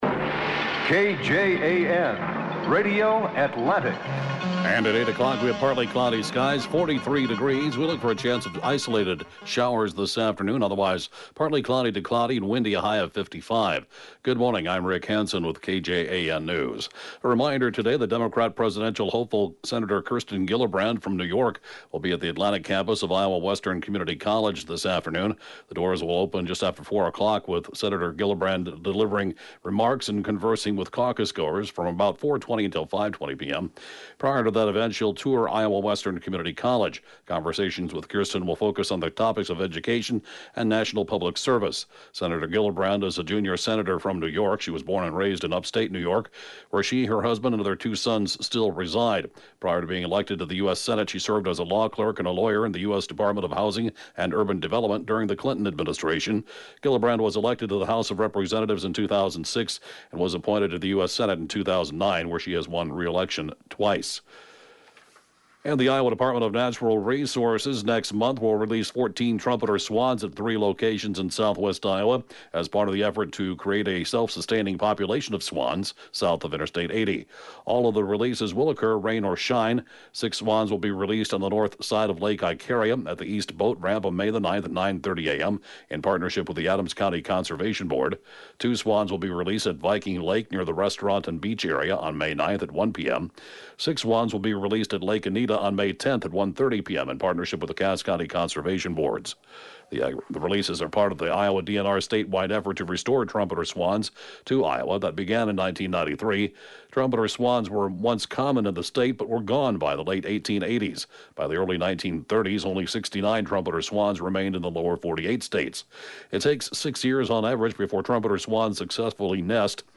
(Podcast) KJAN 8-a.m. News, 4/18/2019